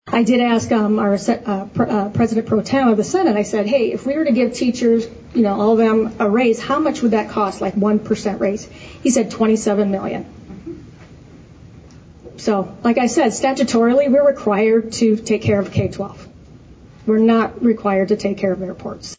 All three of the District 18 State Legislators addressed the Yankton School Board, last night.
State Senator, Lauren Nelson, talks about the cost of giving raises to teachers…